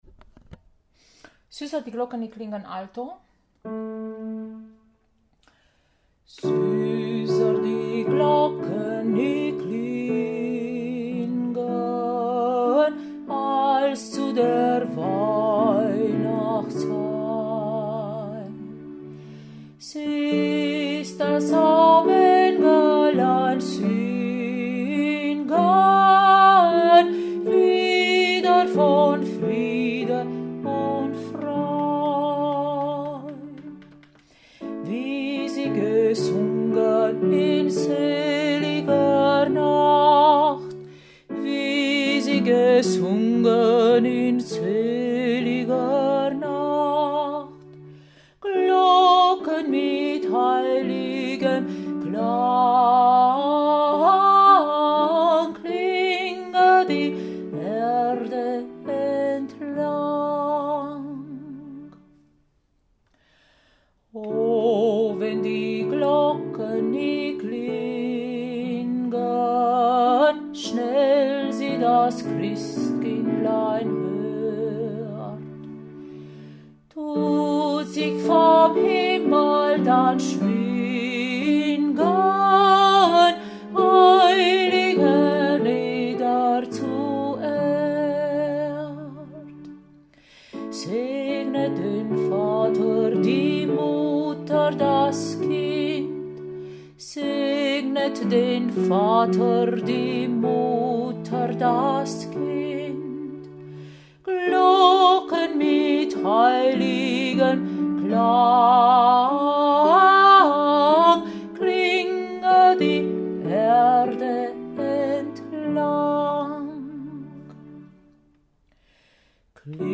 Süßer die Glocken Alto
Süsser-die-Glocken-Alto.mp3